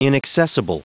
Prononciation du mot inaccessible en anglais (fichier audio)
Prononciation du mot : inaccessible